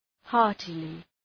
Προφορά
{‘hɑ:rtlı}
heartily.mp3